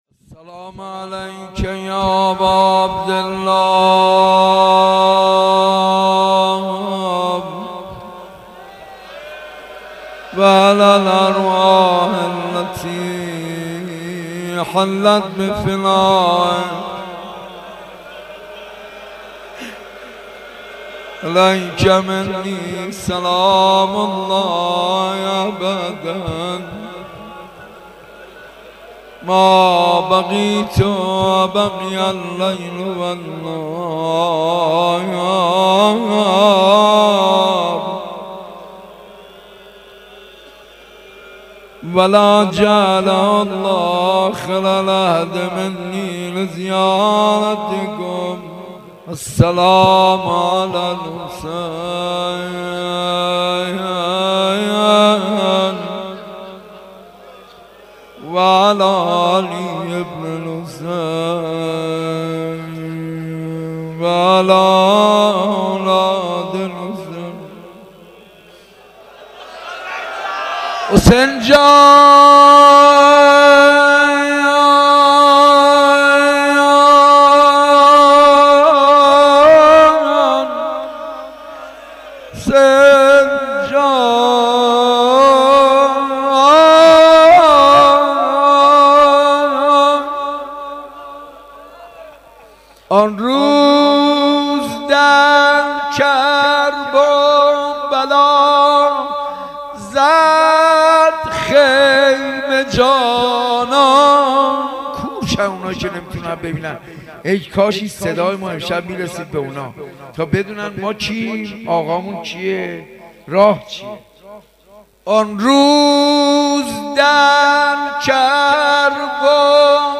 اگر برآید چو مرغی ز پیکر خسته ام پر روضه محمود کریمی